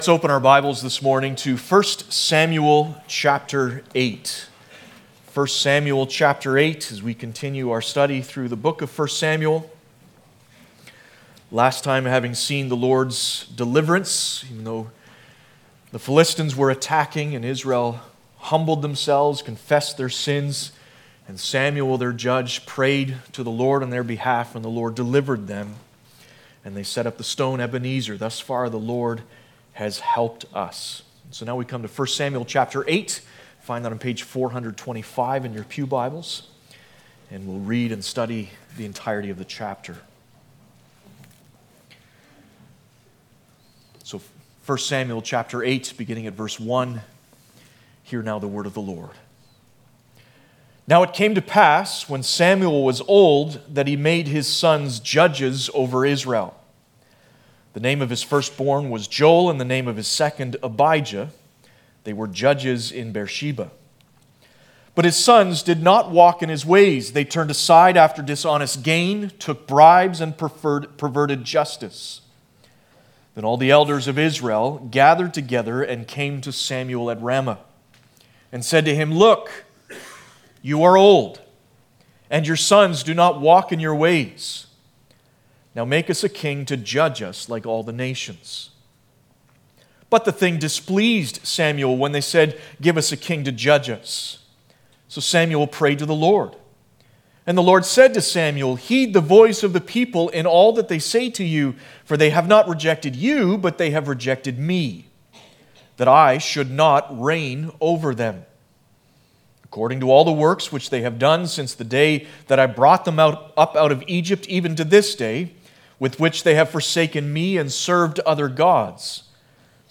Passage: 1 Samuel 8 Service Type: Sunday Morning